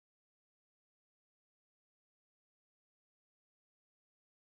• 108 Bpm Drum Beat D Key.wav
108-bpm-drum-beat-d-key-MpL.wav